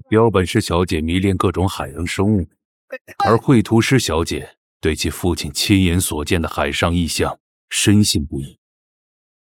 Voiceover AI Immersivo per Storie di Detective
Dai vita ai tuoi misteri con una voce AI grintosa e carica di suspense, progettata per narrazioni noir, storytelling investigativo e narrativa poliziesca hard-boiled.
Text-to-Speech
Narrazione Noir